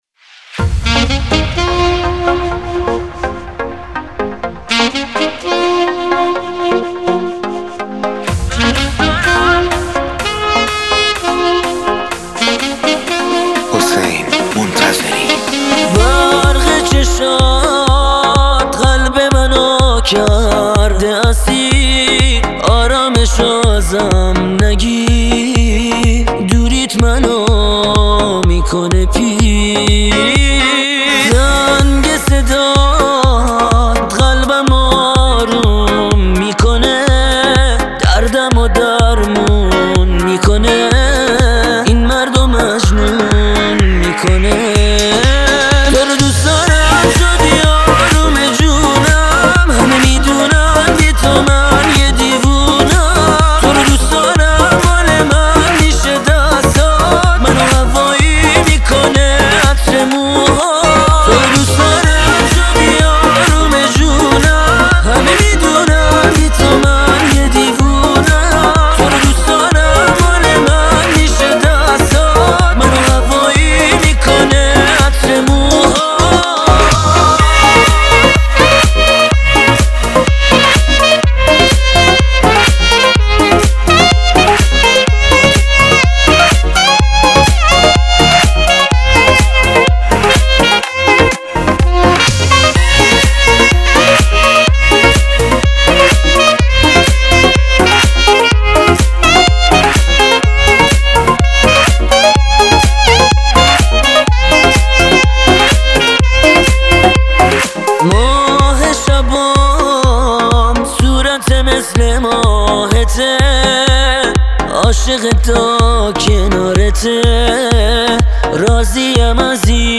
ساکسیفون